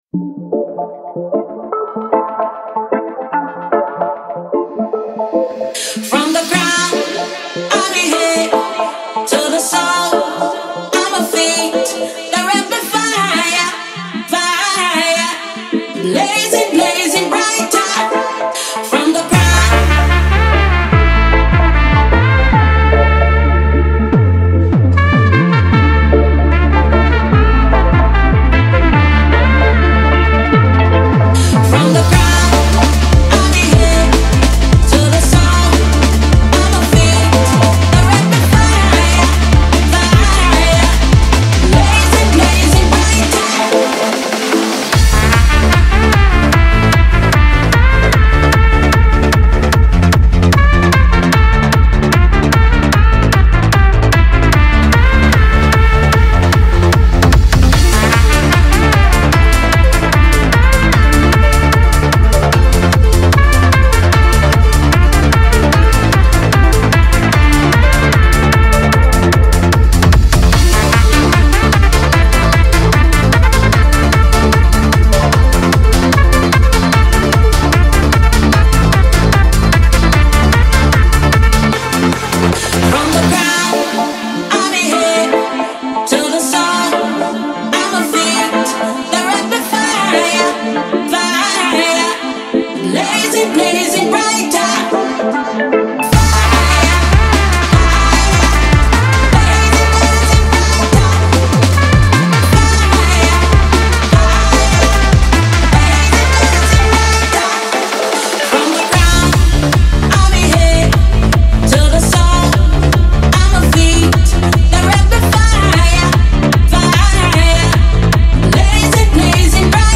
• Жанр: Electronic, Dance, Reggae